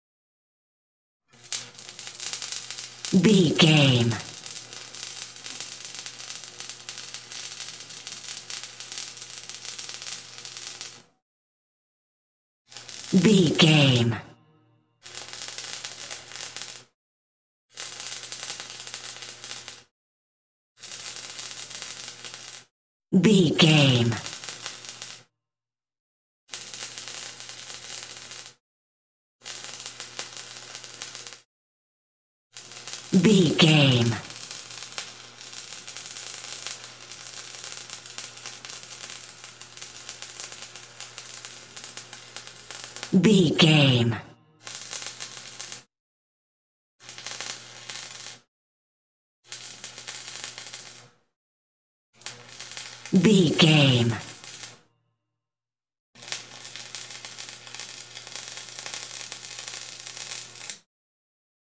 Welder constant medium
Sound Effects
industrial
electroshock